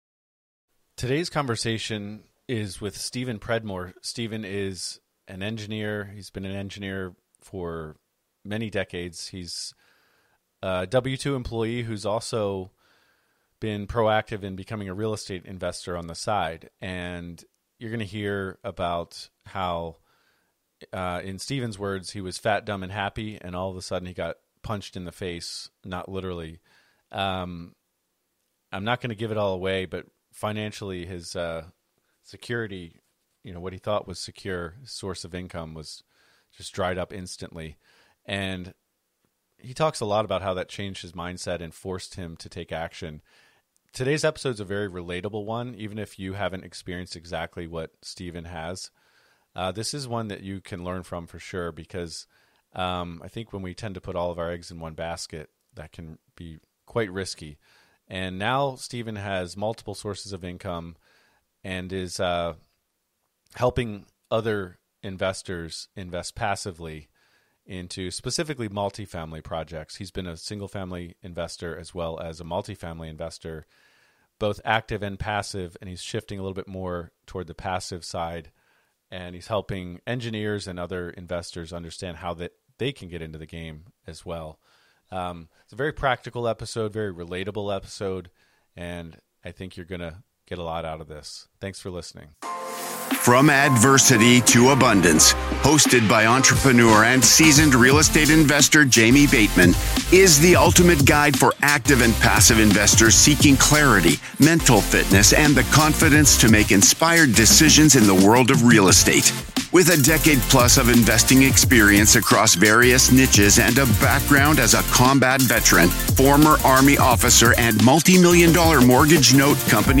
Each week, join us as we dive into the compelling world of real estate through the lens of mental fitness, where challenges transform into opportunities. Our show brings you riveting, interview-based stories from seasoned professionals and inspiring newcomers alike, each sharing their unique journey from struggle to success in